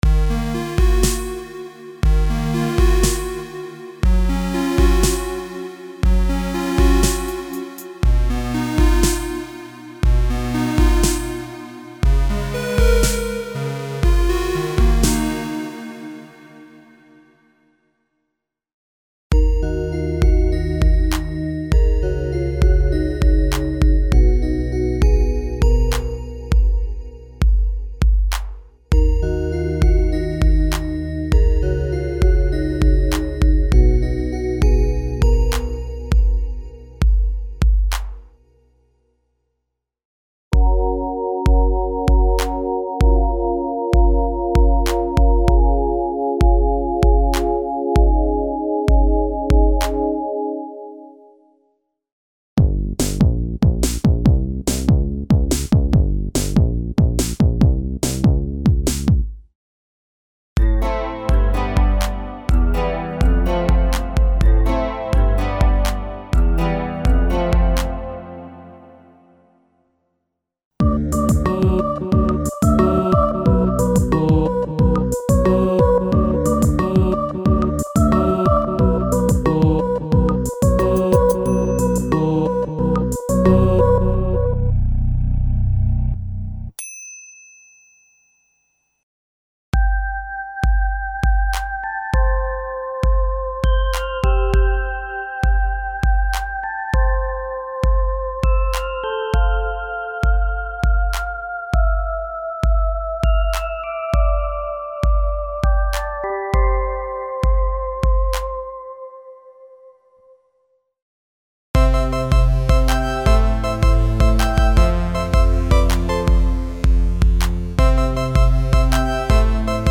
Pop - programs for mainstream music styles and electro pop (pianos, guitars, basses, el. strings, pads, synth leads, voices, stacks and hybrid combinations).
Info: All original K:Works sound programs use internal Kurzweil K2500 ROM samples exclusively, there are no external samples used.